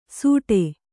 ♪ sūṭe